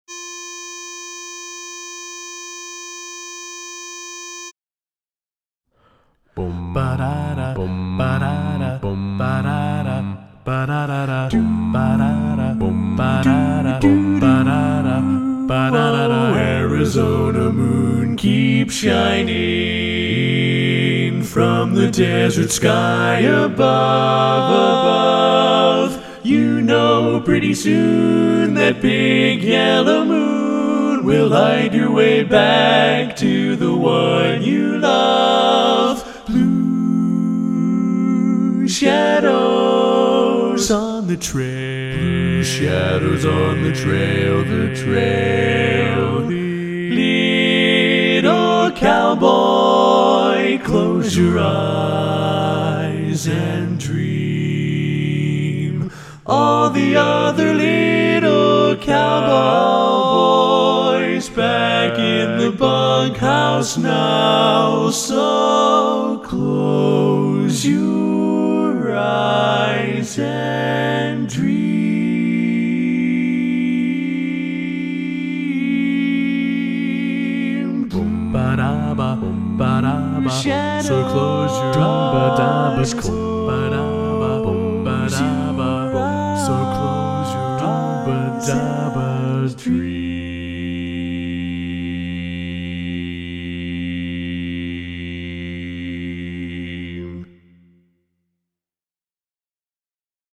Minus Tenor   Lead Pred